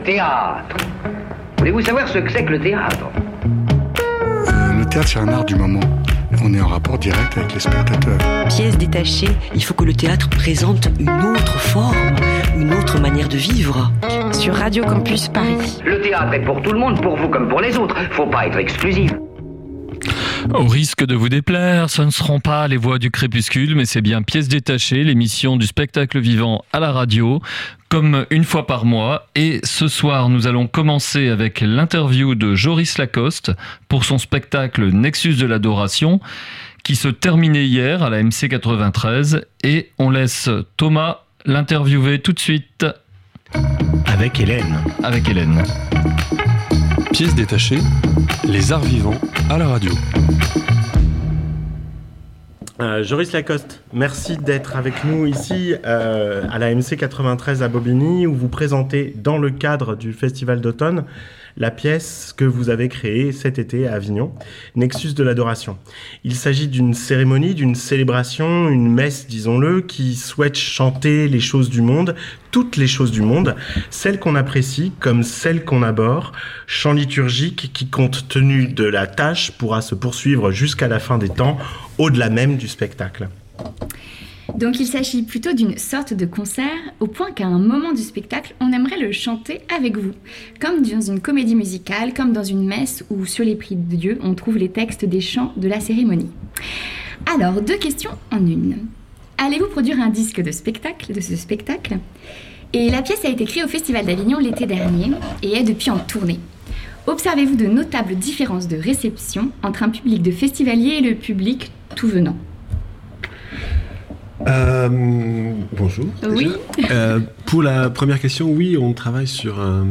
Entretien :